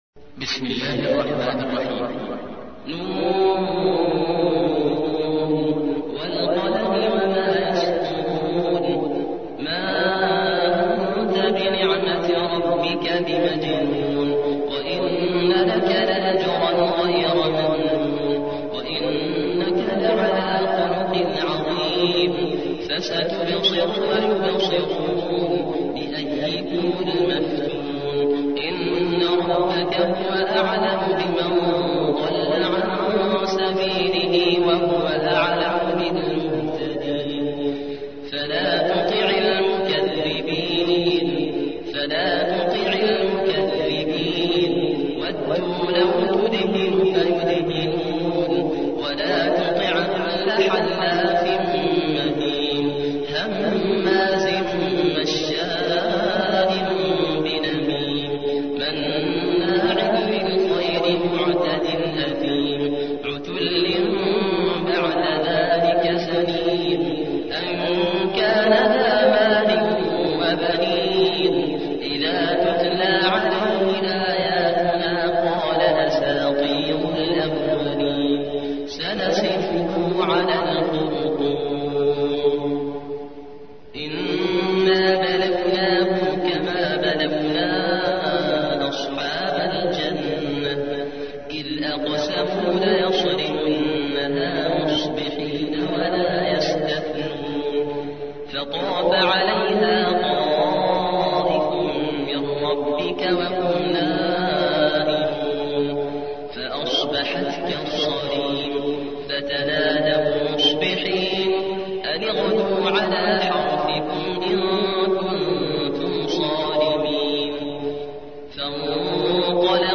68. سورة القلم / القارئ